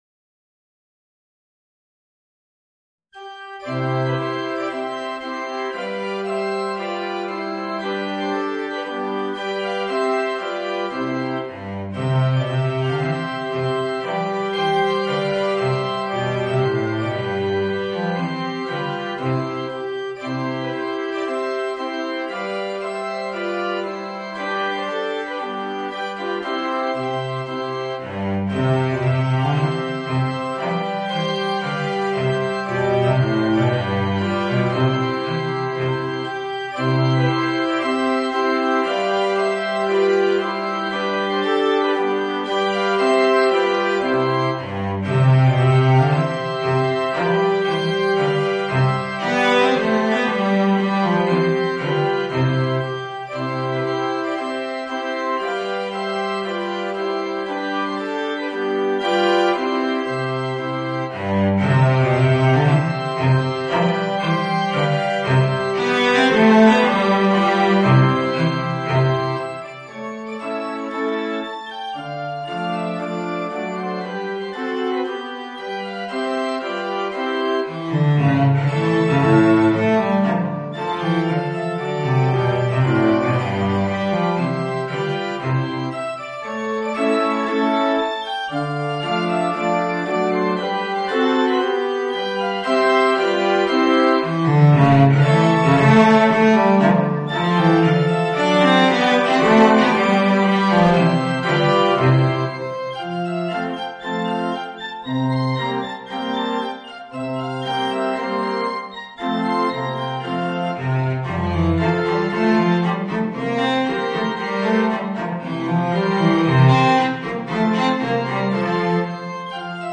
Violoncello and Organ